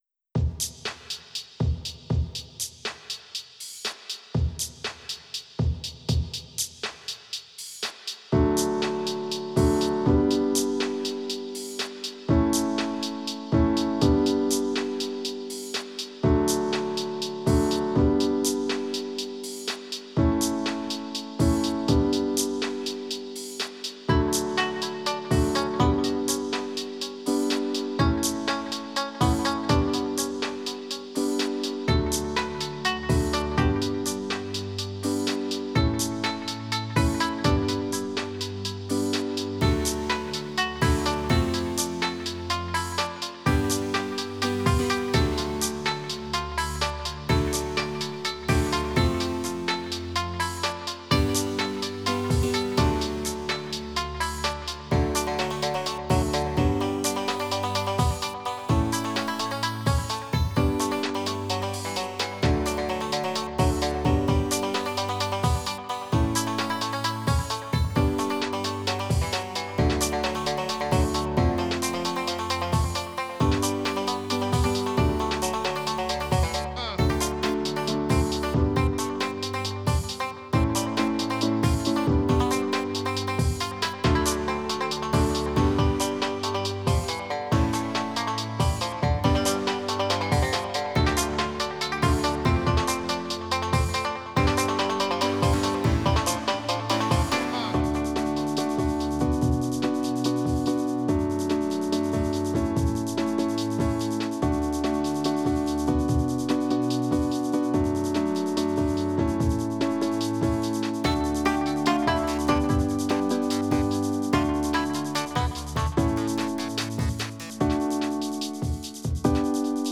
Tags: Piano, Strings, Guitar, Percussion